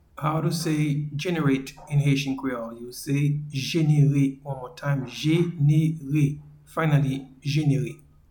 Pronunciation and Transcript:
to-Generate-in-Haitian-Creole-Jenere.mp3